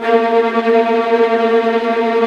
VIOLINT CN-L.wav